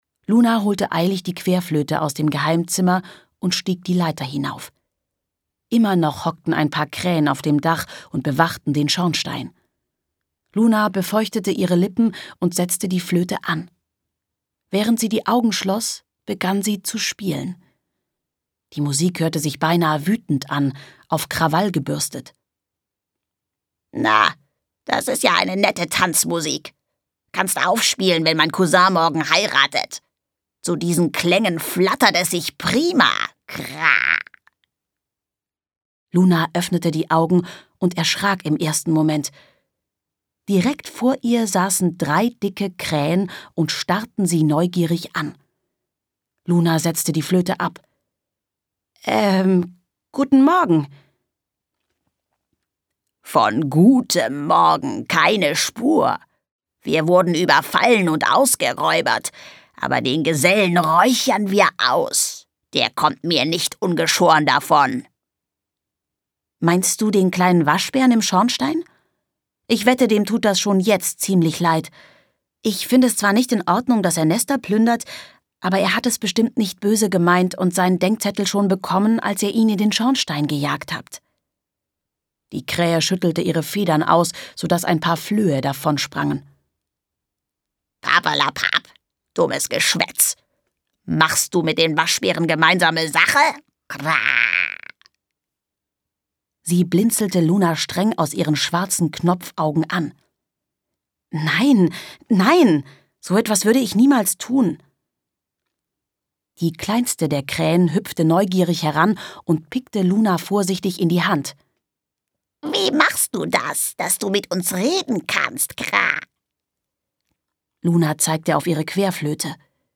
Hörbuch: Luna Wunderwald.